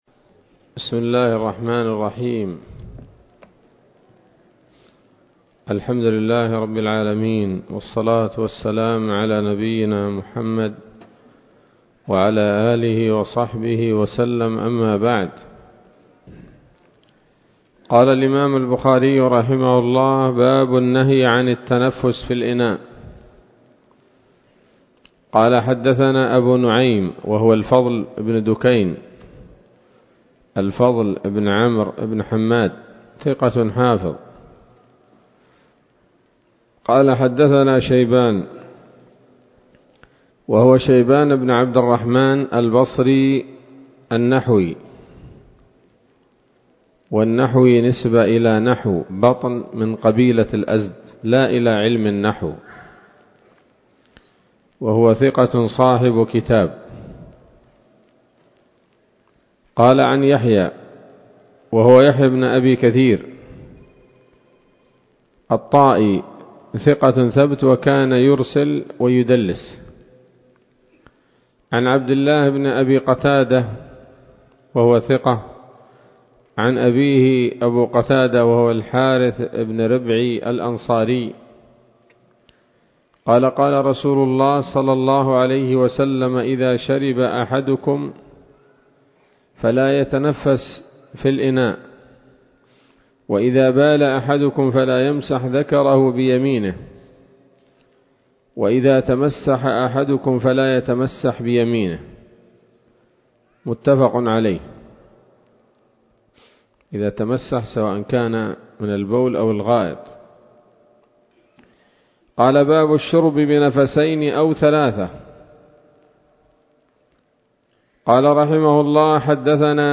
الدرس التاسع عشر من كتاب الأشربة من صحيح الإمام البخاري